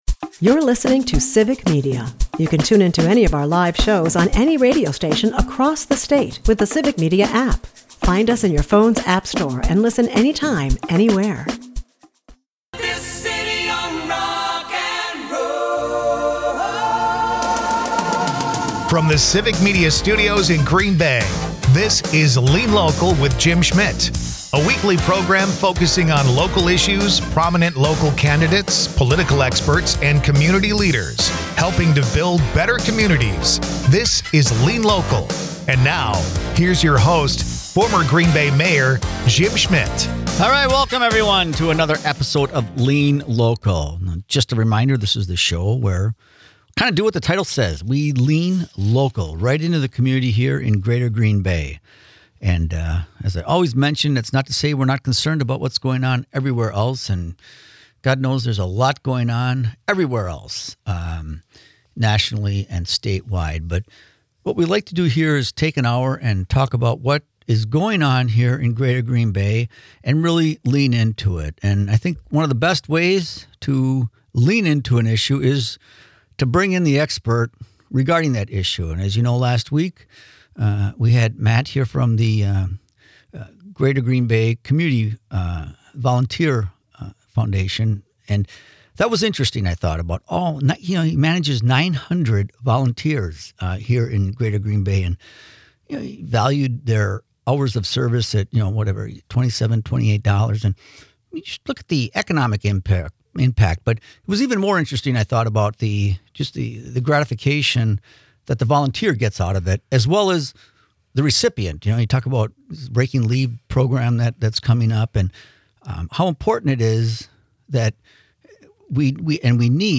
In this episode of Lean Local, host and former Green Bay Mayor Jim Schmitt focuses on local issues and initiatives in Greater Green Bay. He discusses past episodes covering the impact of volunteers and addresses local challenges such as improving reading proficiency and mental health awareness.